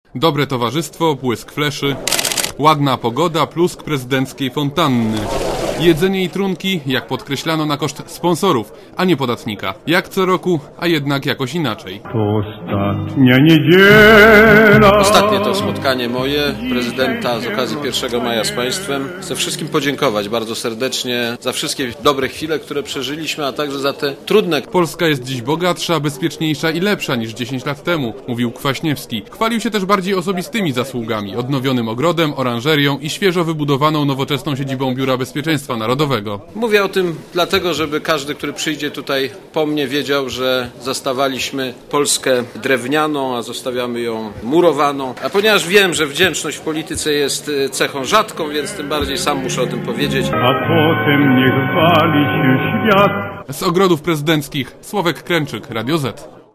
przekonywał prezydent Aleksander Kwaśniewski podczas swojej ostatniej
tradycyjnej majówki w ogrodach Pałacu Prezydenckiego.